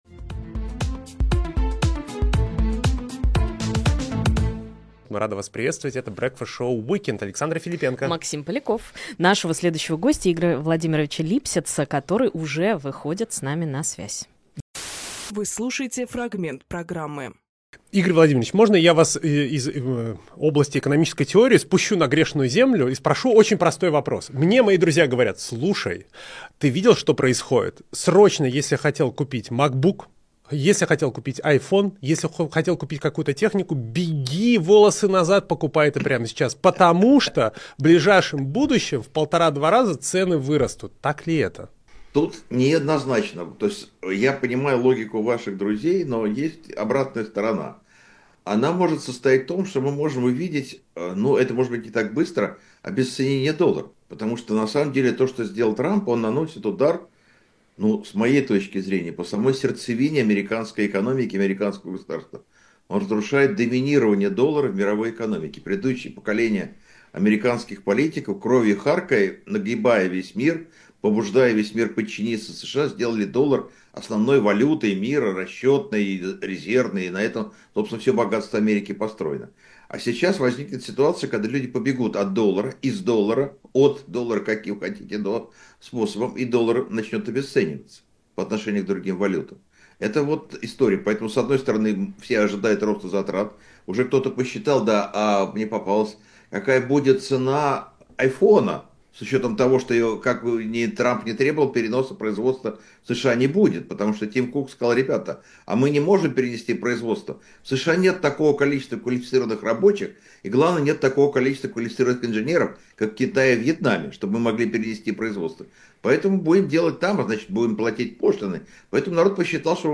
Фрагмент эфира от 06.04.25